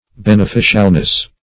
Search Result for " beneficialness" : The Collaborative International Dictionary of English v.0.48: Beneficialness \Ben`e*fi"cial*ness\, n. The quality of being beneficial; profitableness.